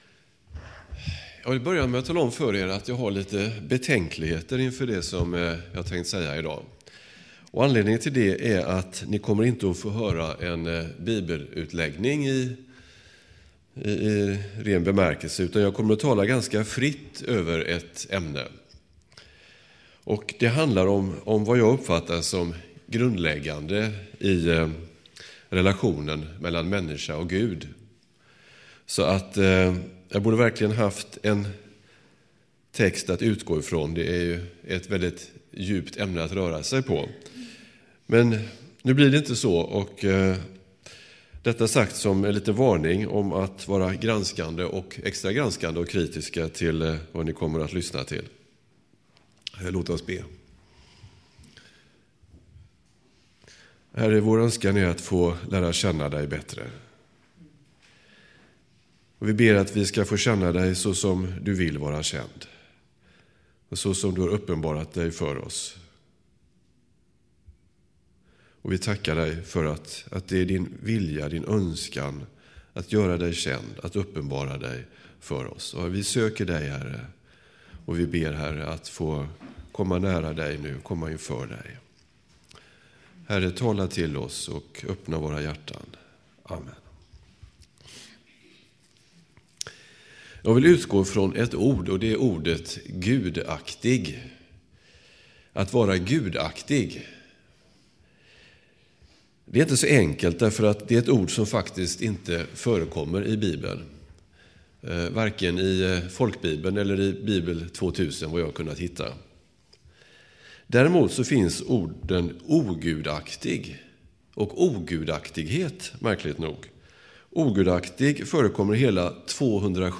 2011-10-16 Predikan av